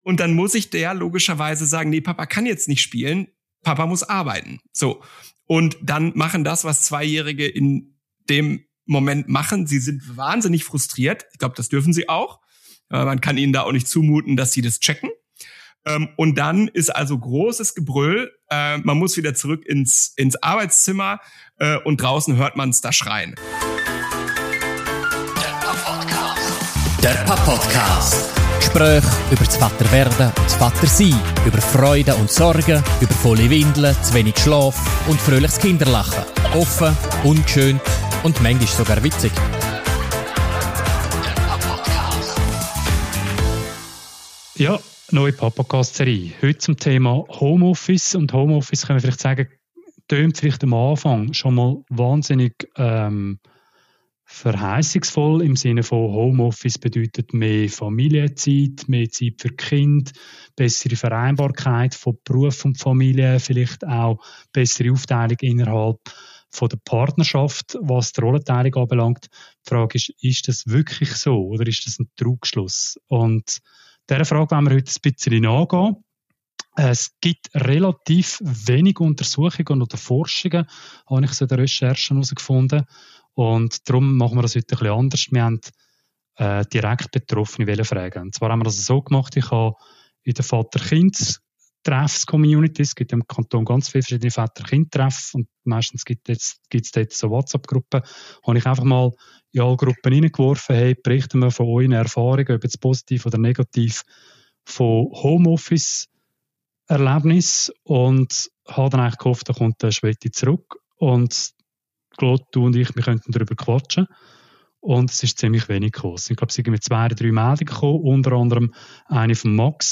Zu dritt sprechen sie aus dem Homeoffice über Chancen und Fallstricke des Arbeitens von zuhause aus, über klare Absprachen und schlechtes Gewissen.